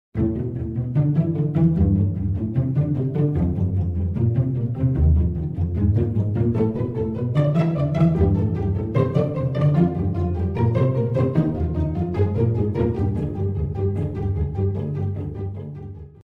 Звуки погони